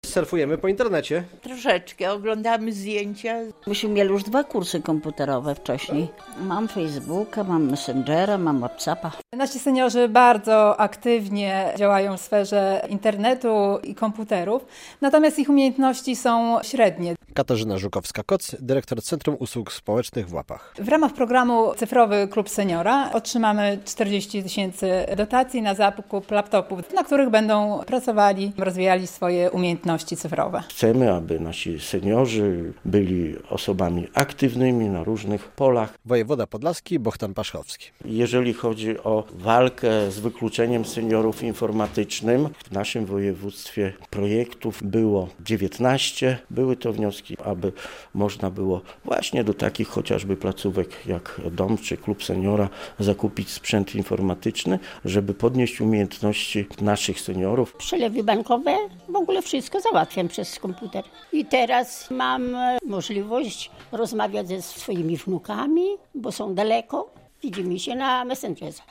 Wiadomości - Podlaskie kluby seniora otrzymają pieniądze na zakup komputerów